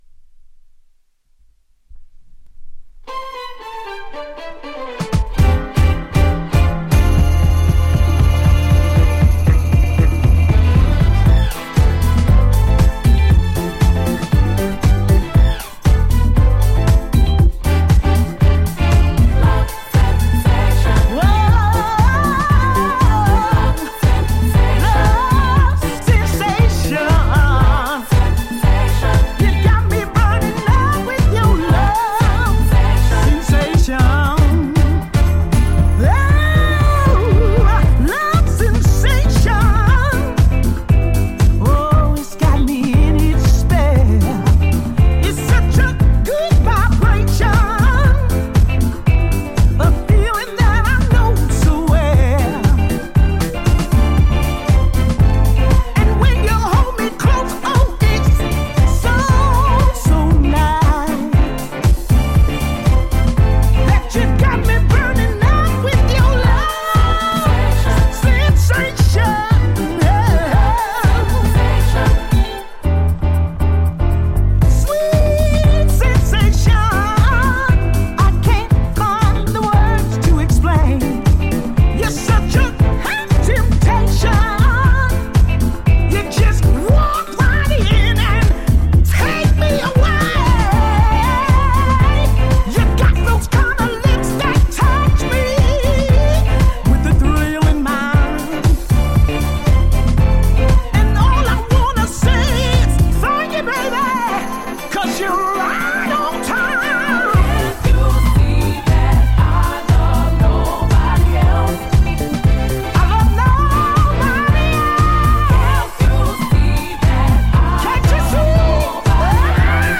原曲のパワフルなヴォーカルとガラージスピリッツに敬意を評した、ゴージャスなサウンド！
7"VERSION
ジャンル(スタイル) SOUL / DISCO / HOUSE